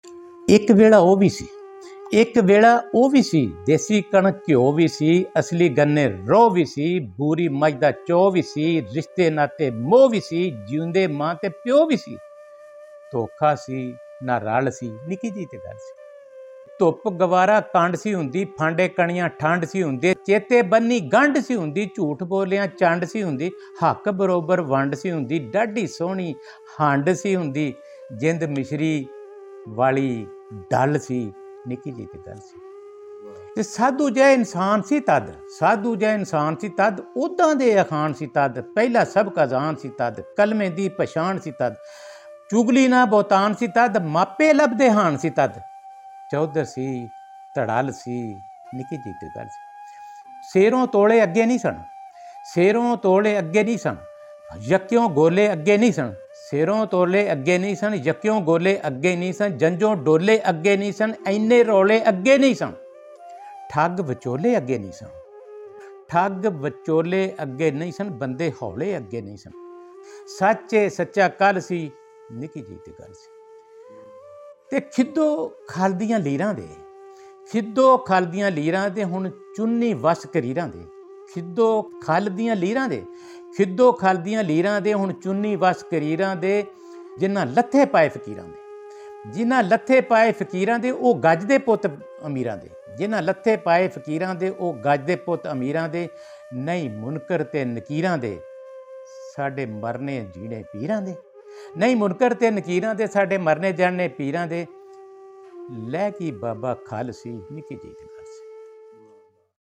Punjabi Poetry